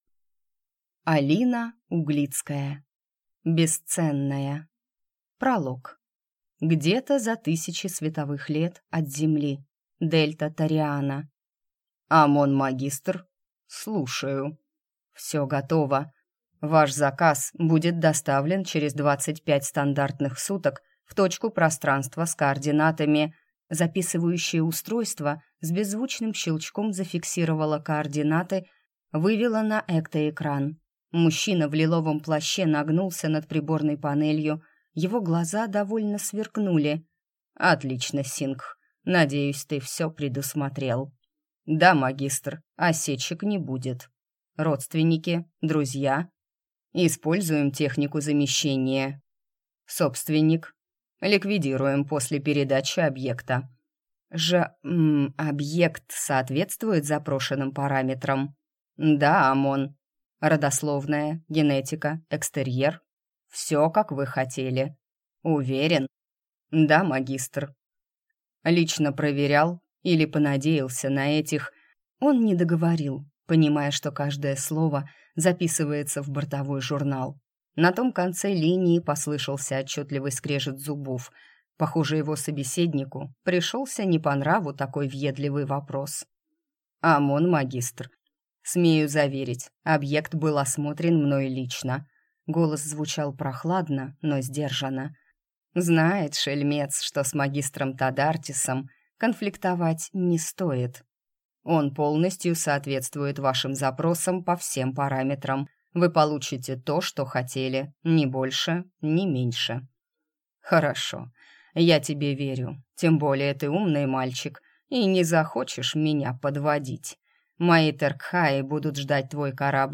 Аудиокнига Бесценная | Библиотека аудиокниг